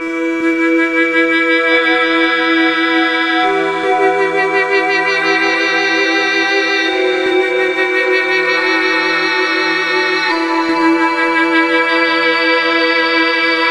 Tag: 70 bpm Ambient Loops Pad Loops 1.88 MB wav Key : E